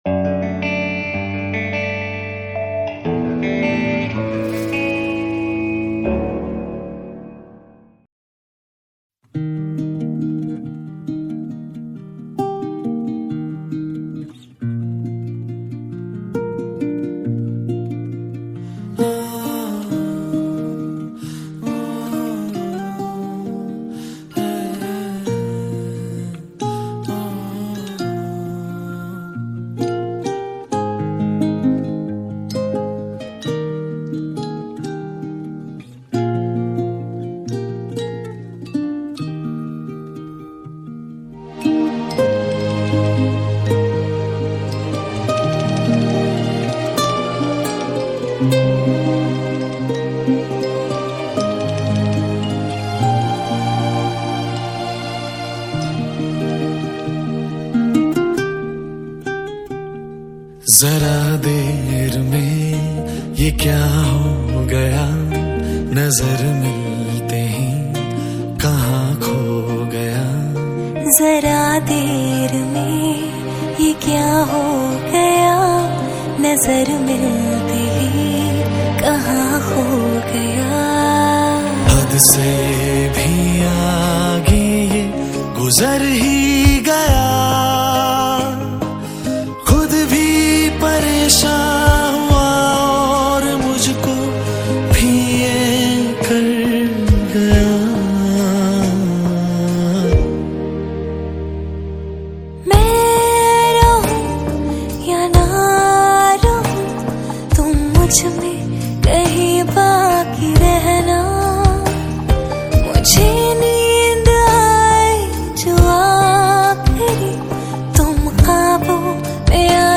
Hindi Mixtape Songs